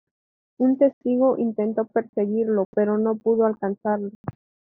Pronounced as (IPA) /ˈno/